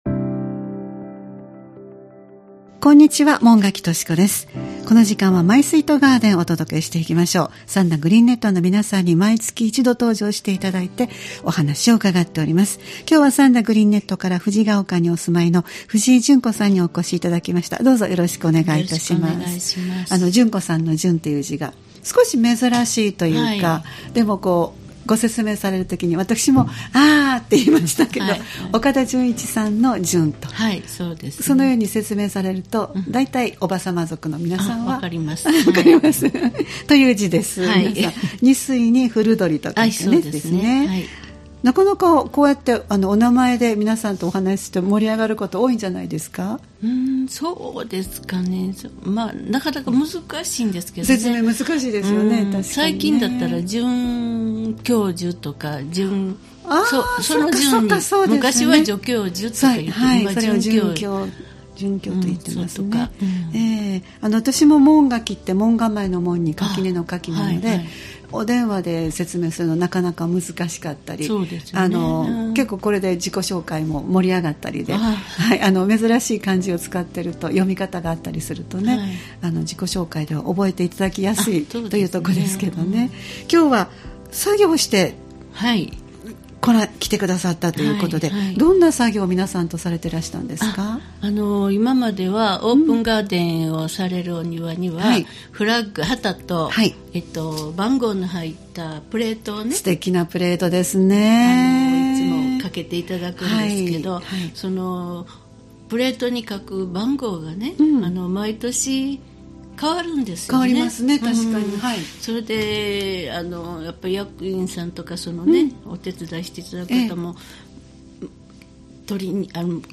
毎月第1火曜日は兵庫県三田市、神戸市北区、西宮市北部でオープンガーデンを開催されている三田グリーンネットの会員の方をスタジオにお迎えしてお庭の様子をお聞きする「マイスイートガーデン」をポッドキャスト配信しています（再生ボタン▶を押すと番組が始まります）